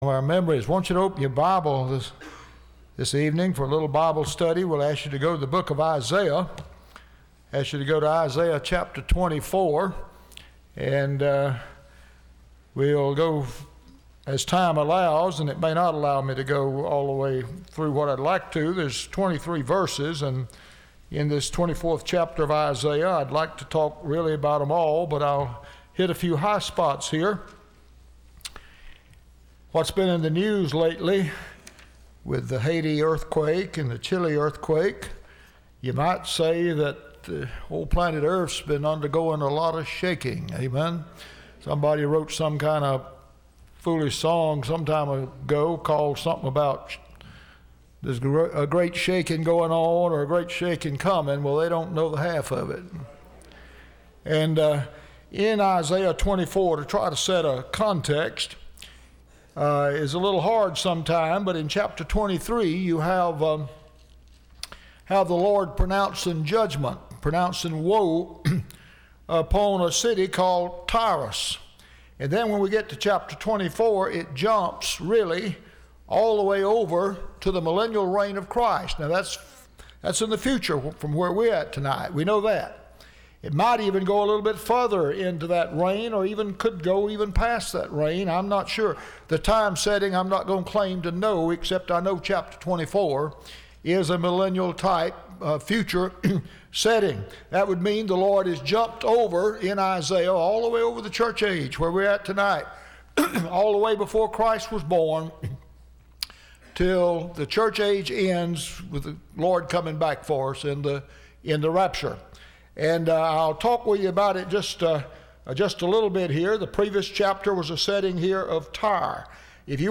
Service Type: Wednesday